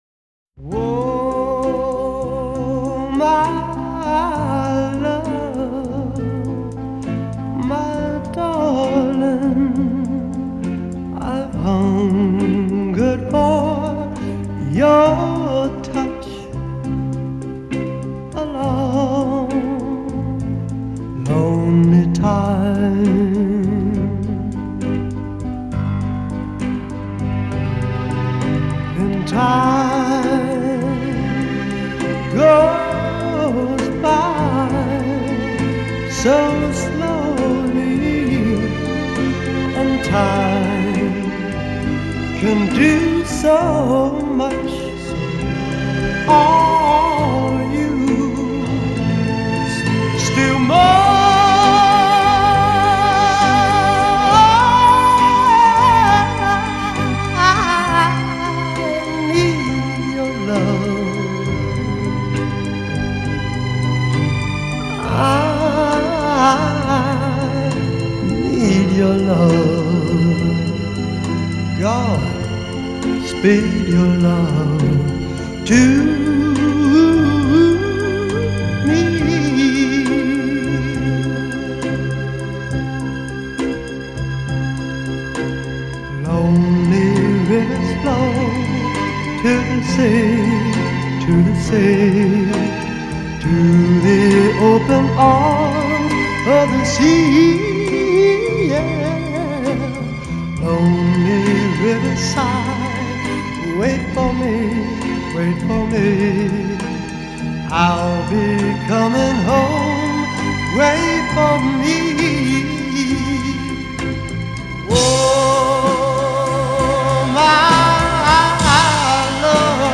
类      型：发烧音乐
19首经典老情歌 浪漫依旧 温情依旧 只是平添了几分怀旧和惆怅......